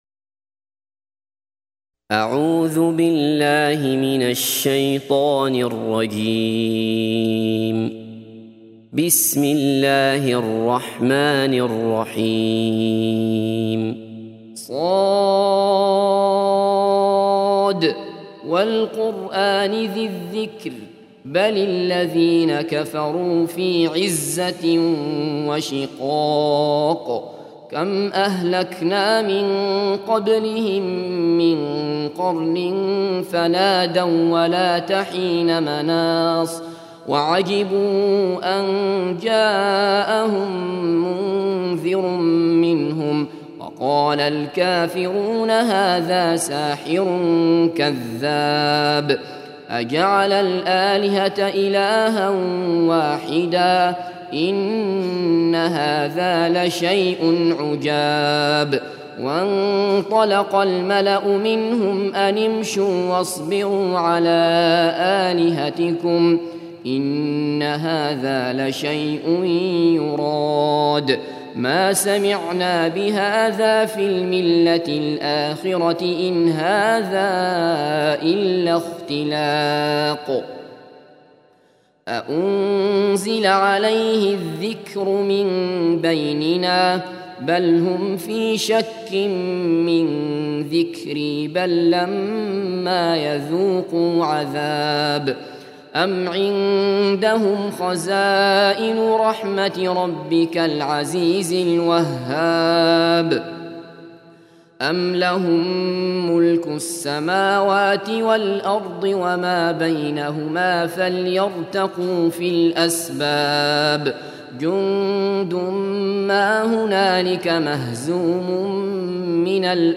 38. Surah S�d. سورة ص Audio Quran Tarteel Recitation
Surah Sequence تتابع السورة Download Surah حمّل السورة Reciting Murattalah Audio for 38.